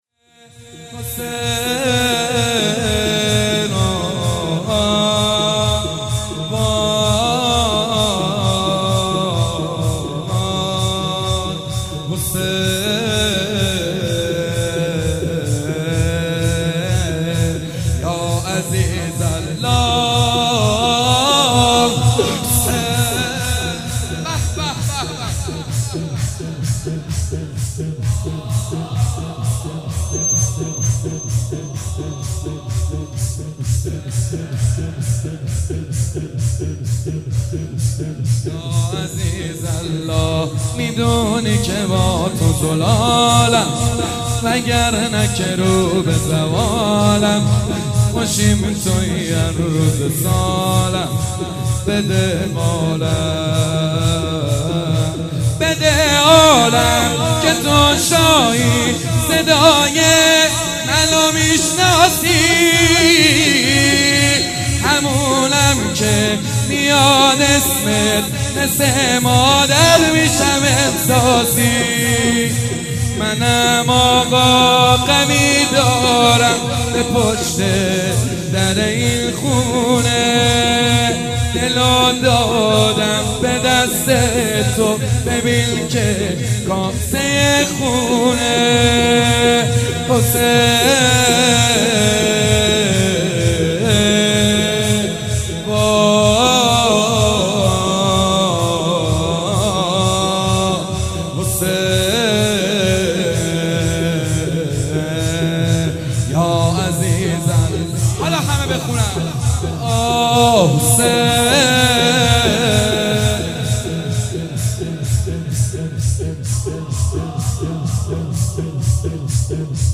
درحسینیه فاطمه الزهرا (س) برگزار شد
روضه
شور